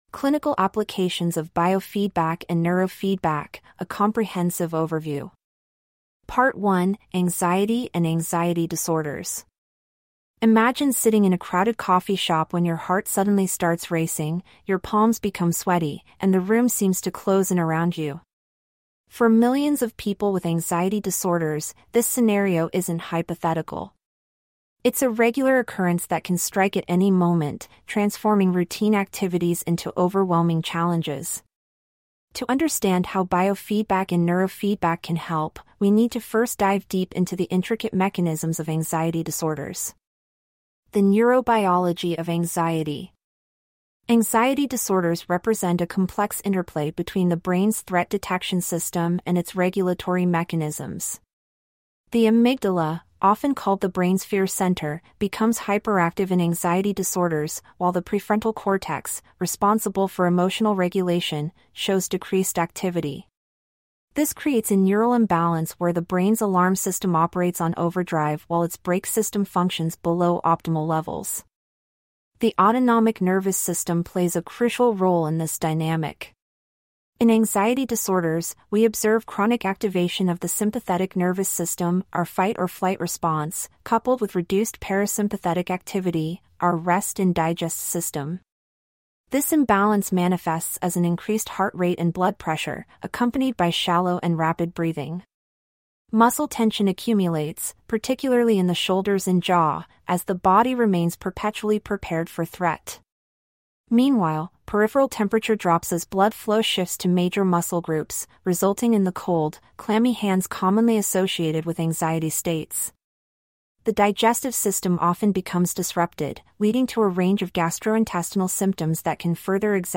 This unit covers The Quantitative EEG, Neurofeedback Training Strategies, Attention Deficit Hyperactivity Disorder, Mild Closed Head Injuries and Traumatic Brain Injury, Substance Use Disorder, Epilepsy, Anxiety and Anxiety Disorders, Post-Traumatic Stress Disorder, Depression, and Tinnitus. Please click on the podcast icon below to hear a full-length lecture.